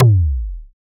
SI2 FM WUMM.wav